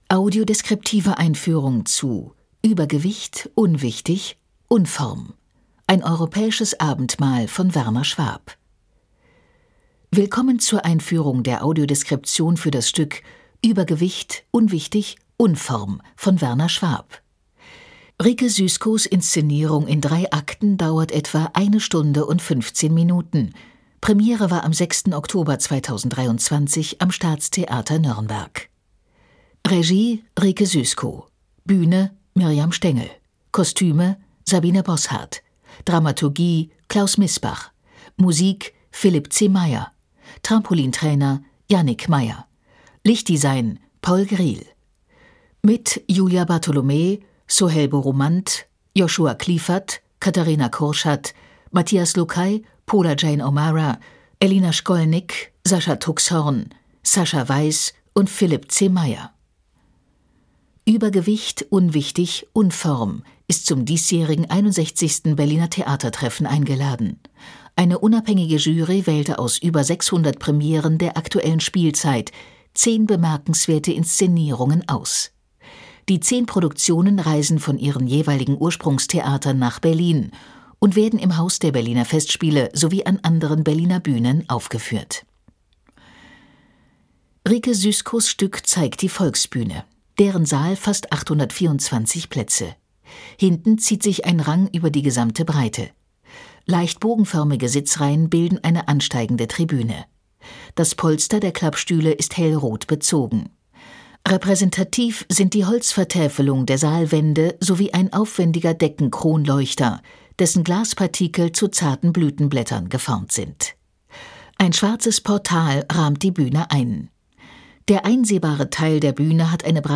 tt24_audiodeskription_uebergewicht.mp3